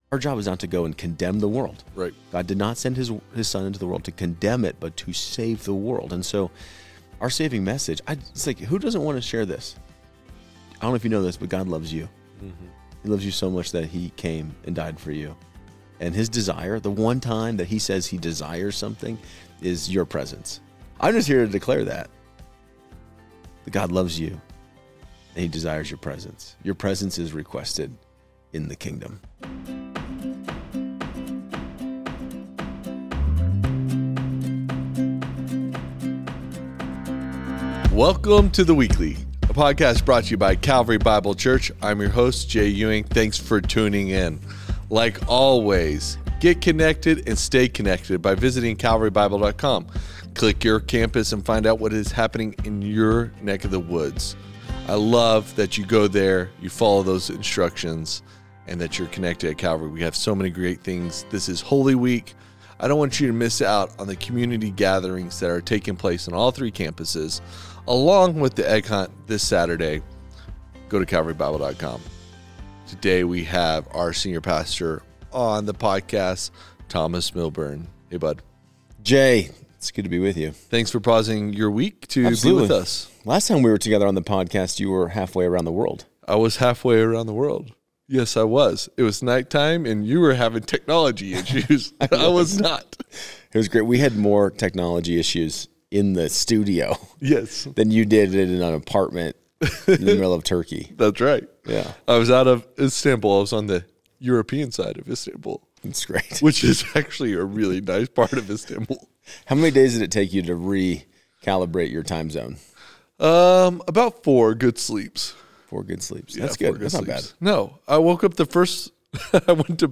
In this Holy Week conversation